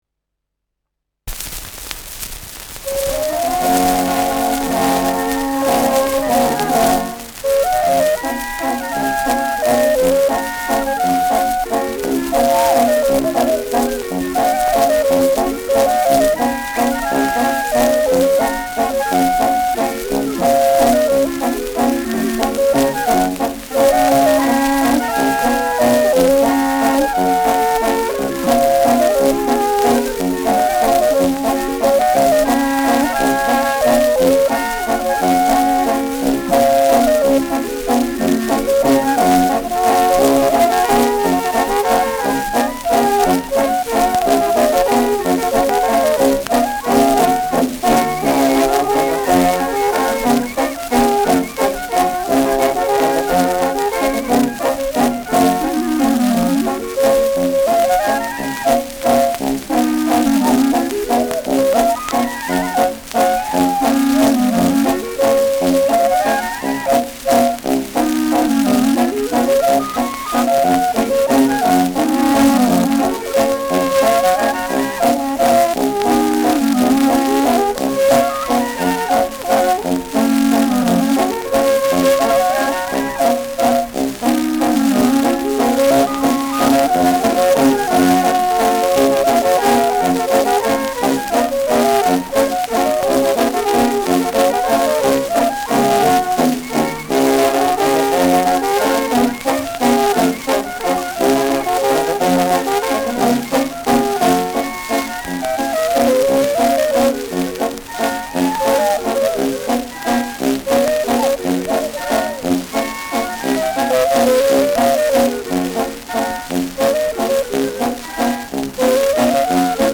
Schellackplatte
ausgeprägtes Rauschen
Dachauer Bauernkapelle (Interpretation)